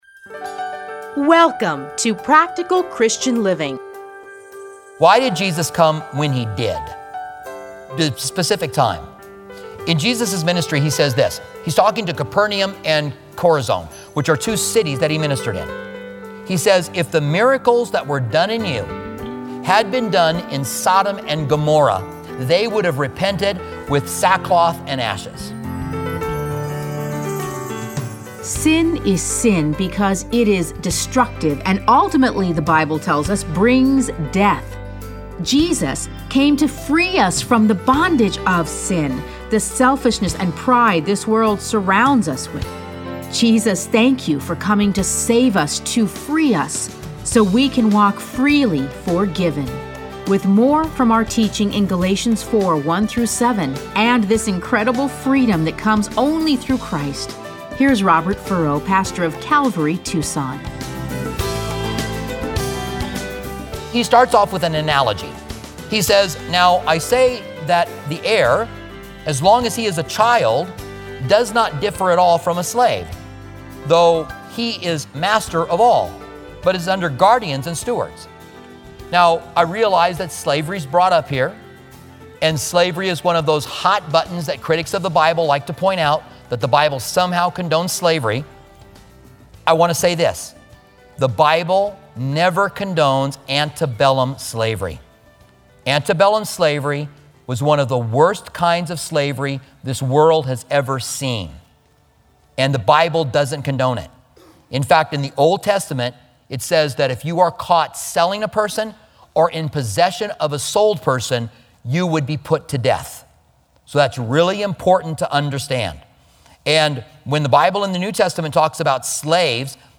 Listen to a teaching from Galatians 4:1-7.